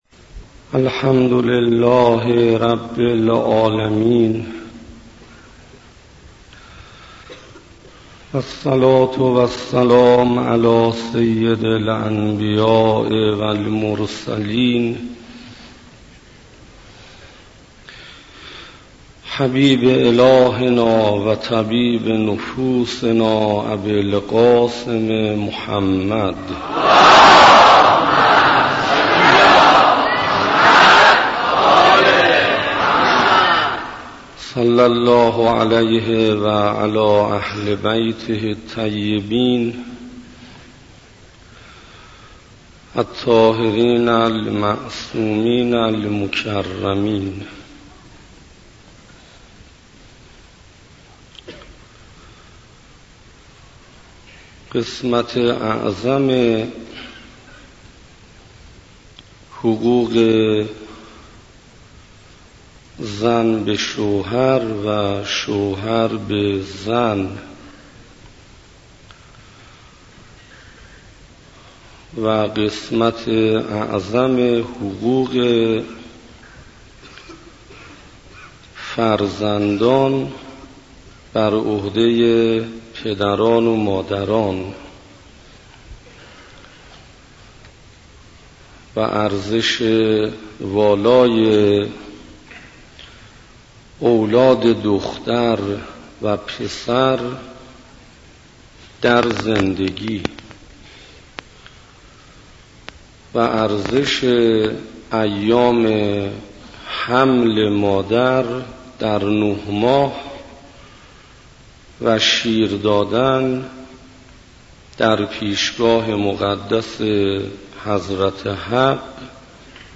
گلچین سخنرانی ها - گلچین سخنرانی ها: حقوق پدر و مادر بر فرزند - 0 -